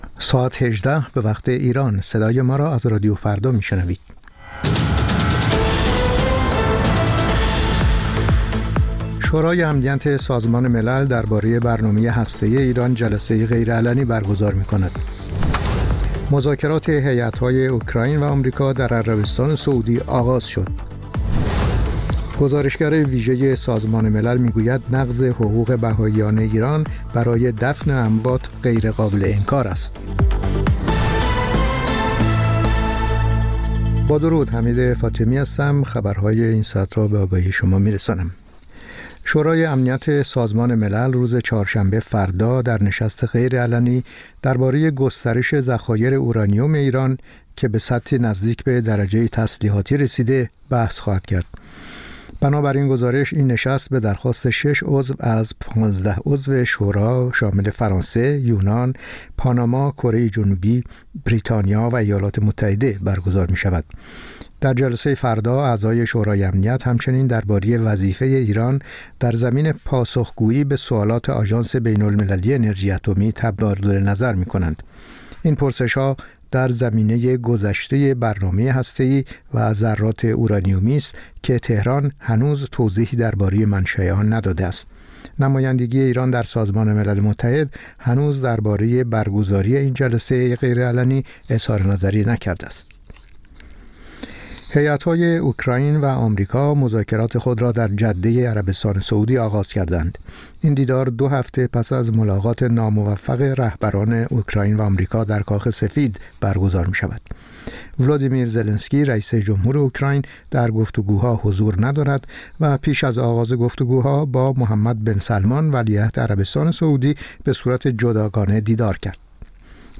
سرخط خبرها ۱۸:۰۰